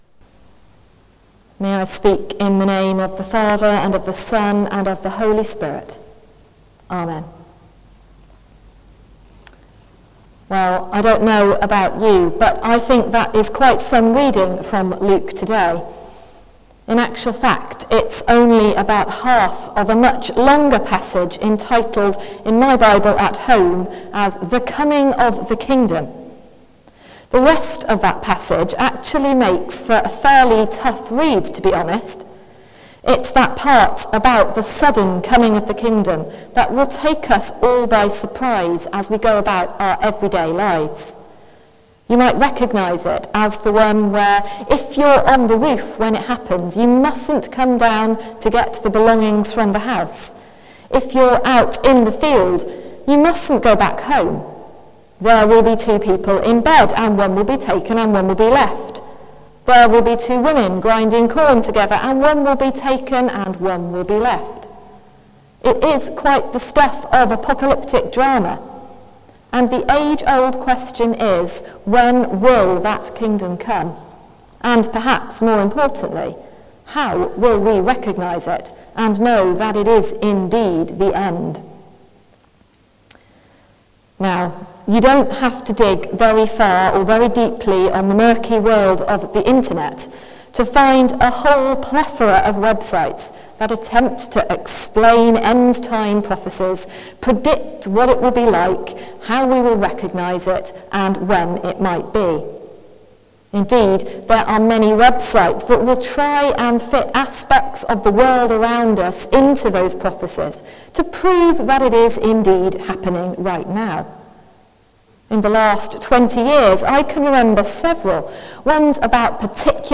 Sermon: The Kingdom is Among Us | St Paul + St Stephen Gloucester